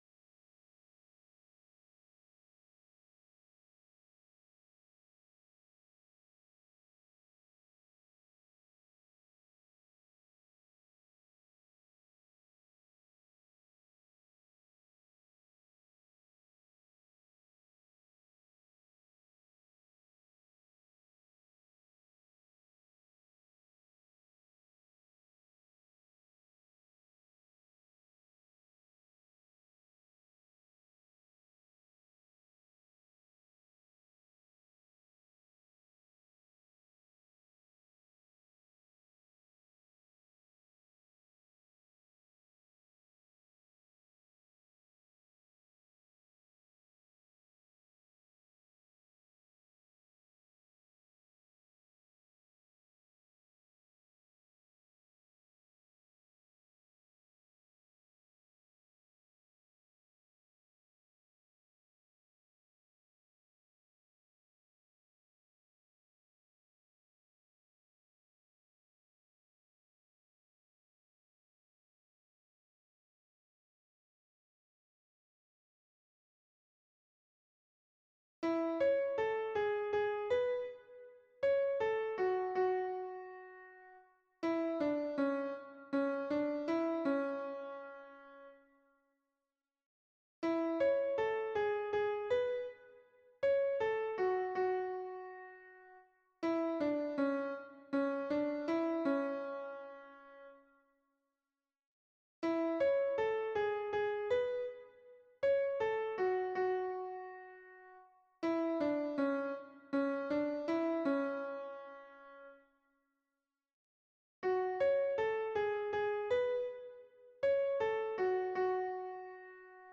- berceuse traditionnelle norvégienne
MP3 version piano
Alto